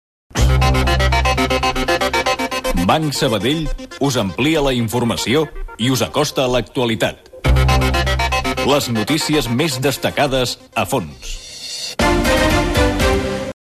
Gènere radiofònic Publicitat